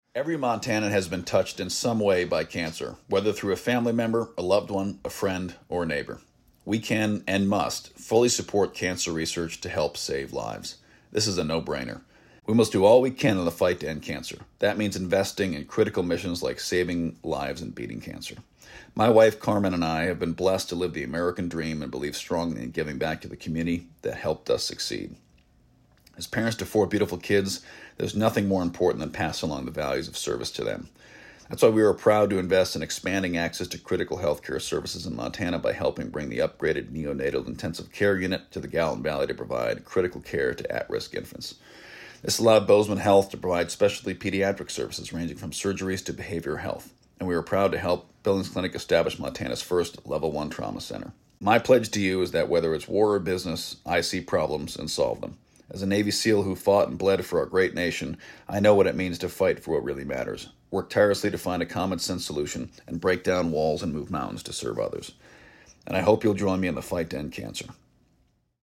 The candidates' responses were aired on Voices of Montana, October 9-11, 2024.